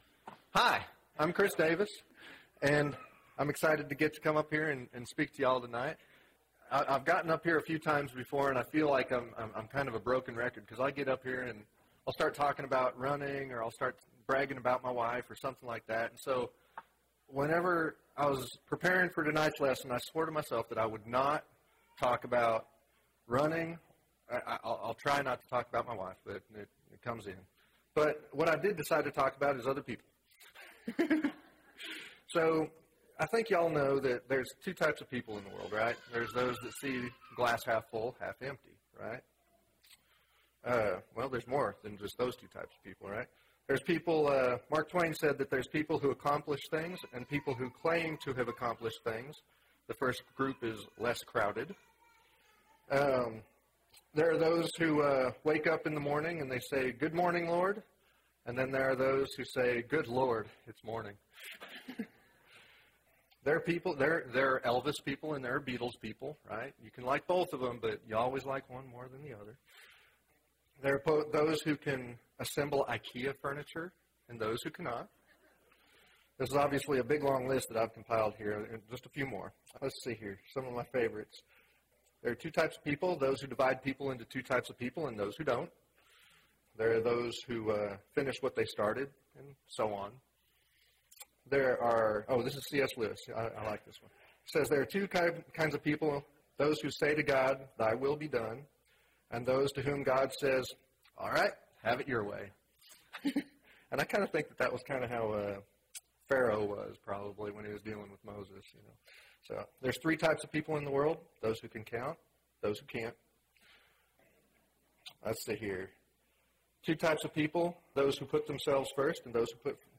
A Study of Selected Parables (3 of 7) – Bible Lesson Recording
Wednesday PM Bible Class